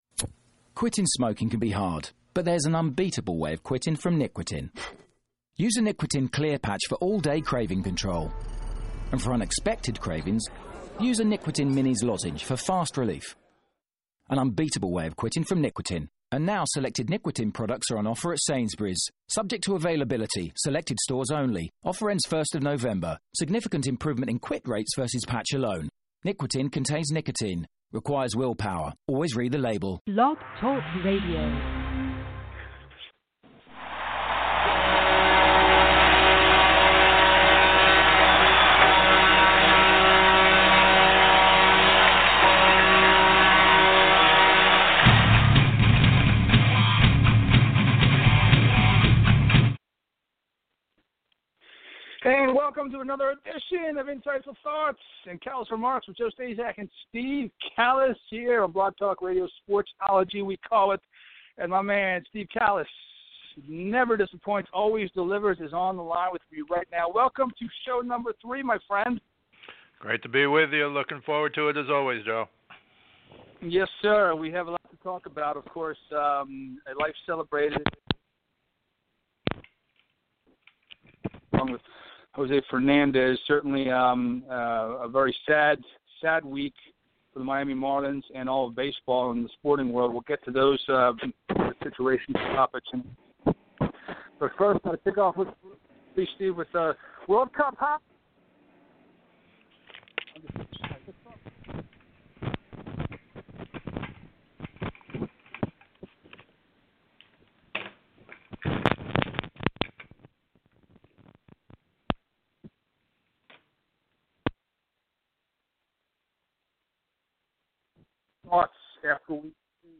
Weeklcall in sports show